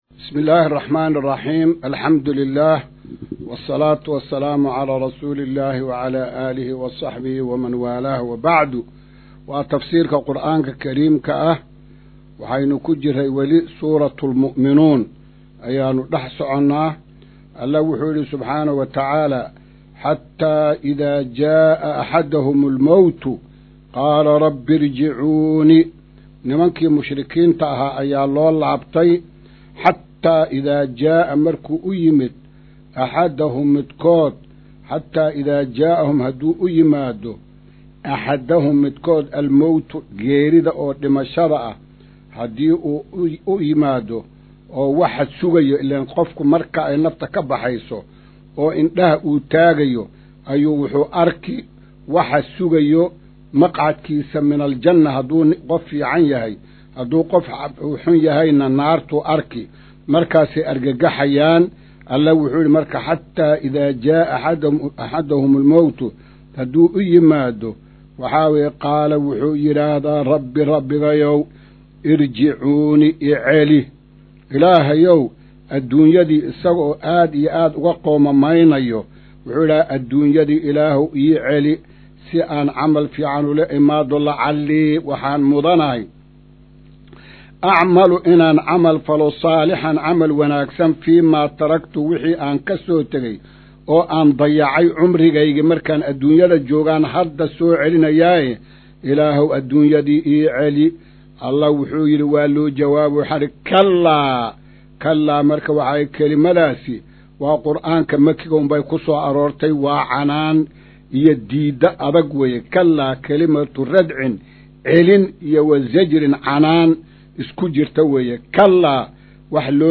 Maqal:- Casharka Tafsiirka Qur’aanka Idaacadda Himilo “Darsiga 168aad”